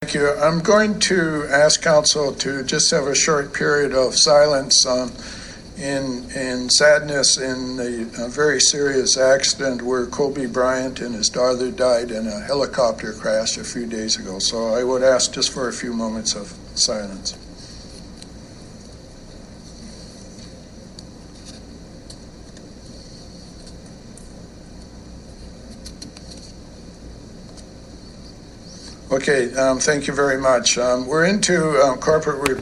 On the 75th anniversary of the liberation of the Auschwitz Nazi concentration camp that killed more than a million Jews, Surrey Mayor Doug McCallum opened up Monday night’s council meeting paying tribute to…Kobe Bryant.